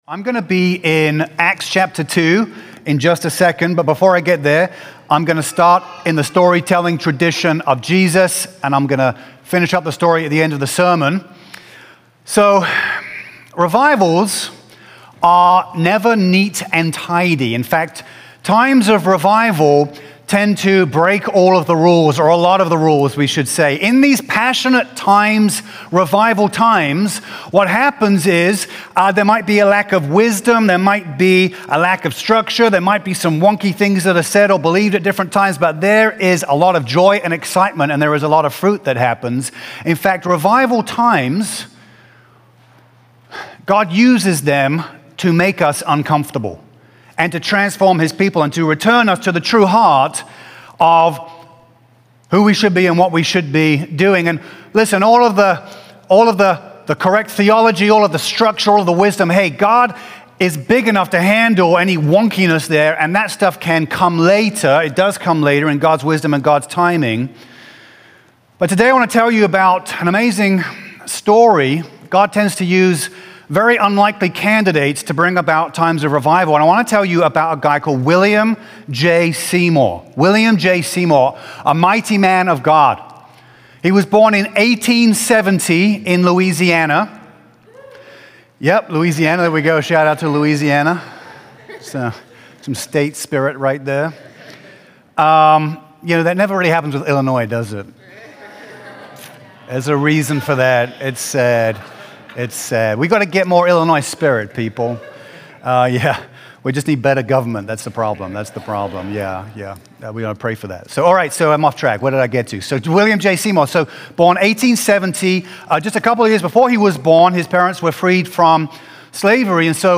A message from the series "Revival Times."